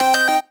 retro_collect_item_stinger_01.wav